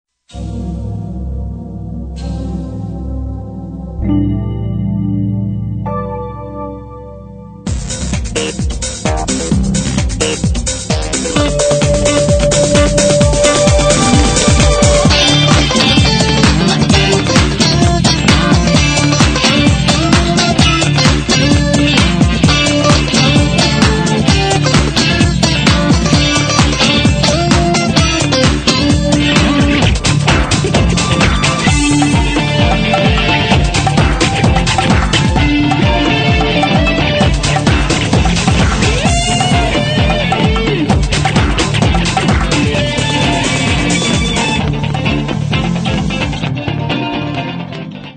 ロック、ラップ、アフリカ音楽、土着的な雰囲気にのある曲です。